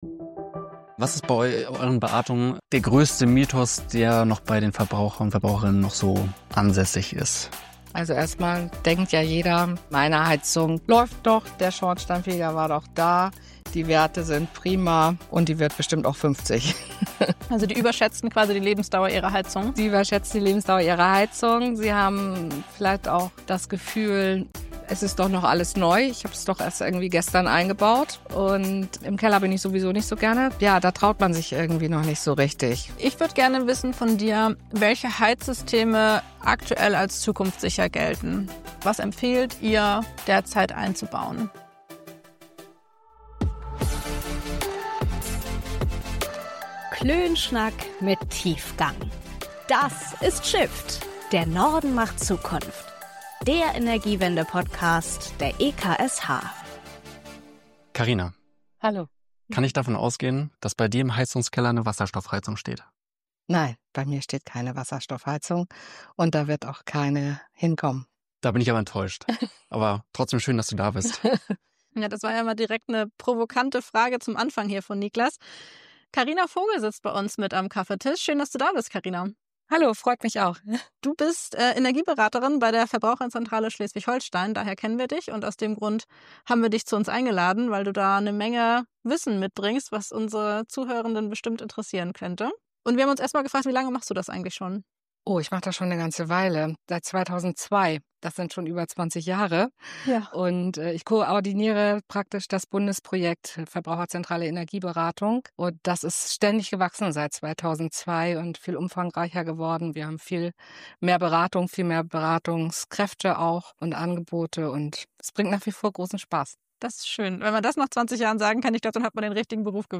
Außerdem klären wir Mythen und Missverständnisse rund ums nachhaltige Heizen. Viel Spaß bei unserem Klönschnack mit Tiefgang!